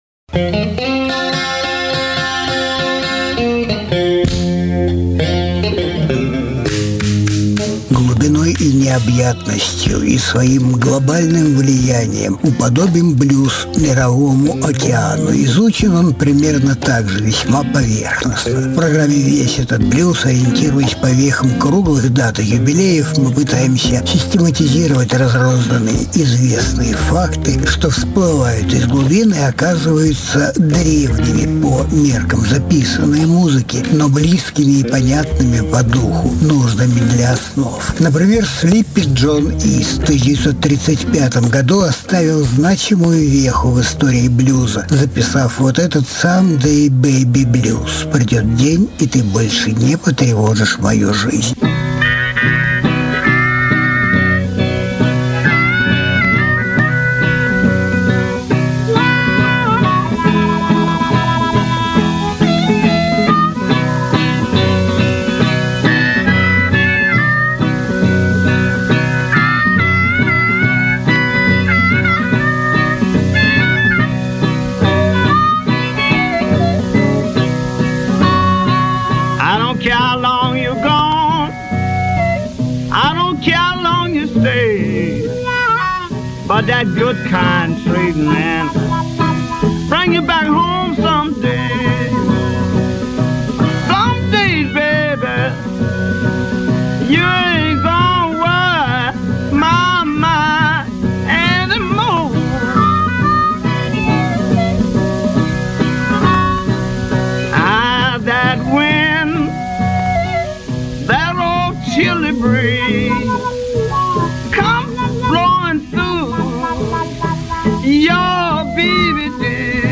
Жанр: Блюзы и блюзики СОДЕРЖАНИЕ 31.01.2022 1.